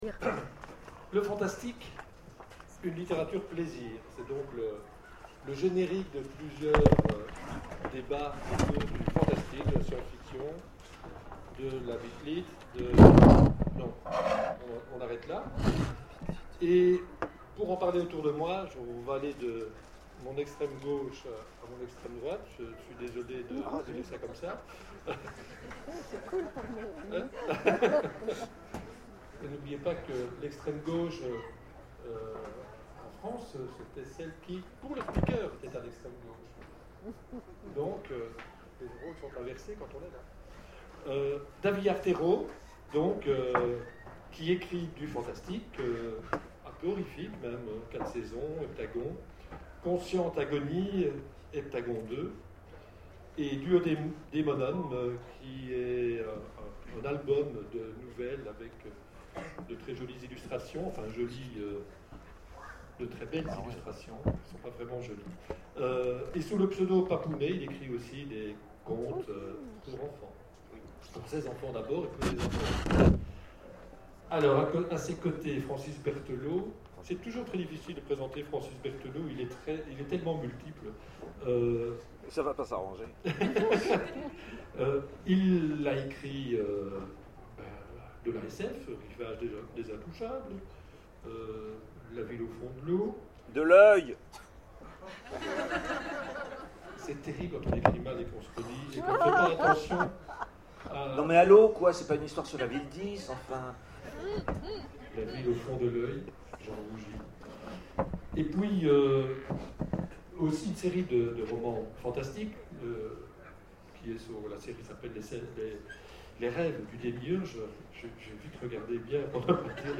Imaginales 2013 : Conférence Le fantastique...